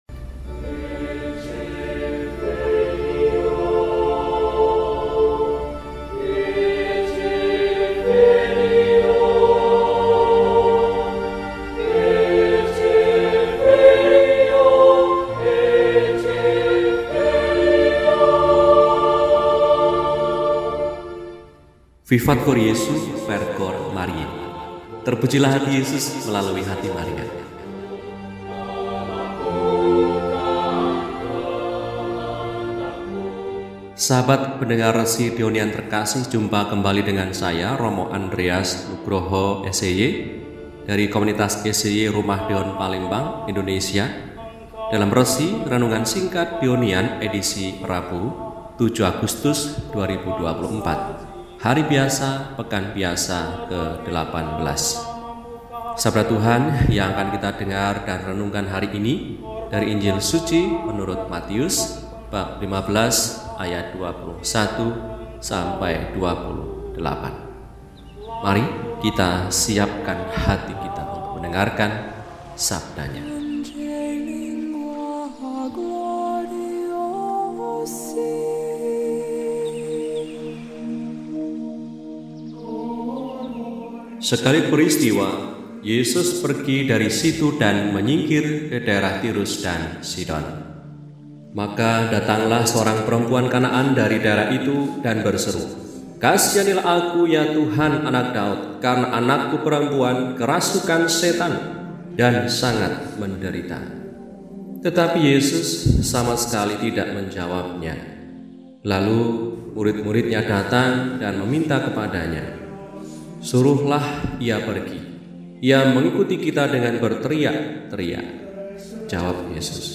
Rabu, 07 Agustus 2024 – Hari Biasa Pekan XVIII – RESI (Renungan Singkat) DEHONIAN